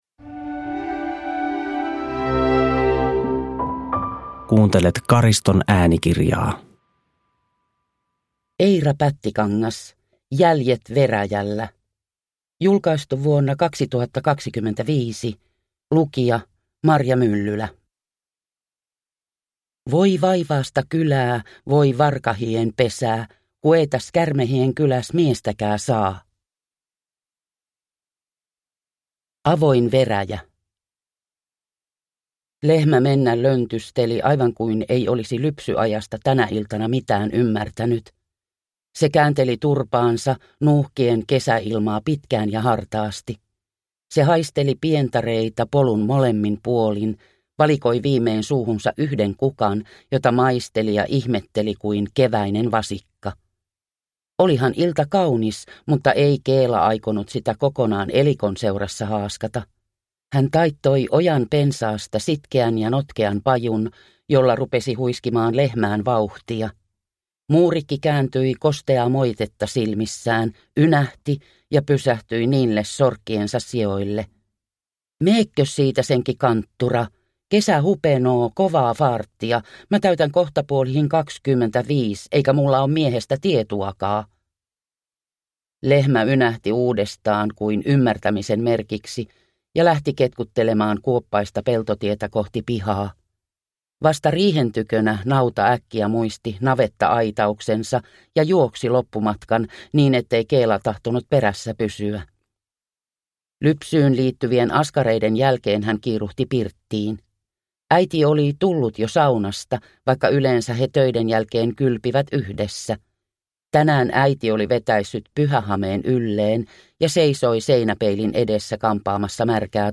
Jäljet veräjällä (ljudbok) av Eira Pättikangas